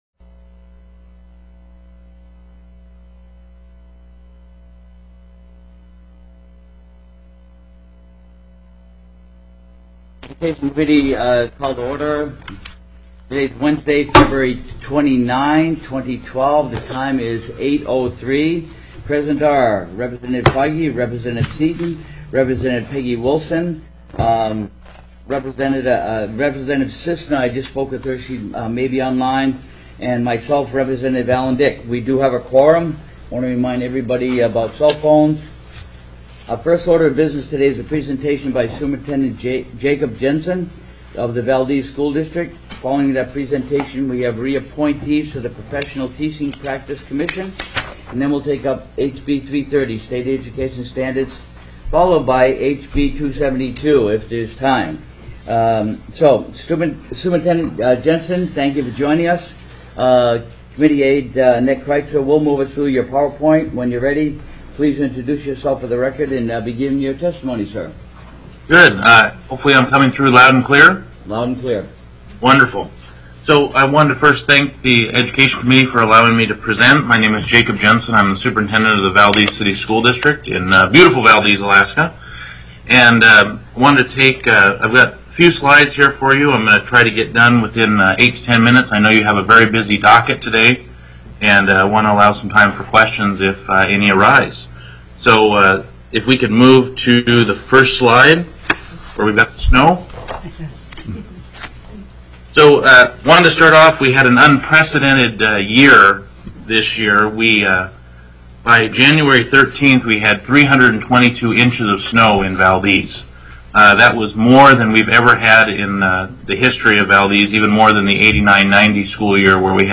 02/29/2012 08:00 AM House EDUCATION
TELECONFERENCED